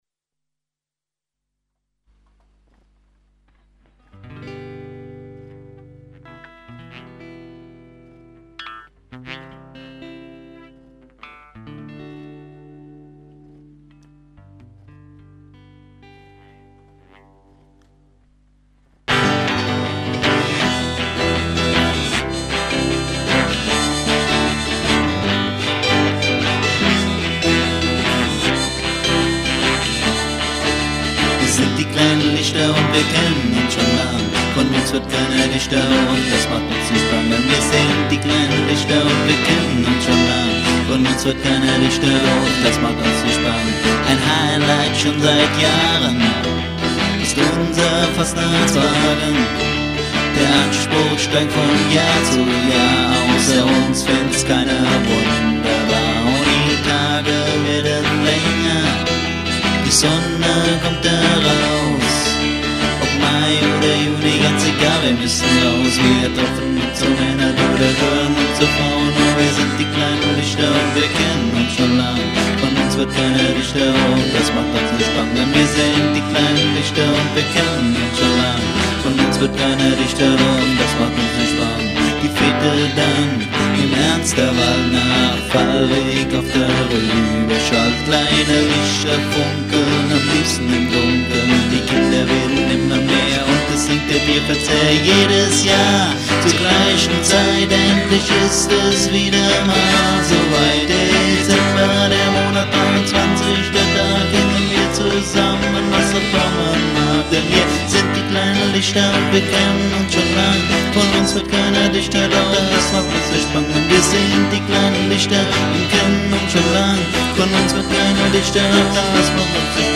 unplugged
Recorded at the Roof-Top-Chamber, Bad Ems 16.12.2000